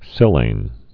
(sĭlān)